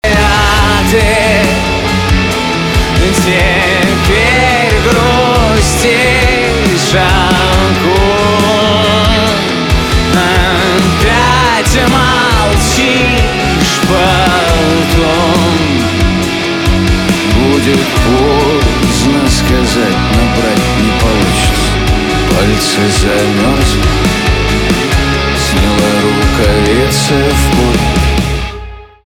русский рок , грустные , печальные , гитара , барабаны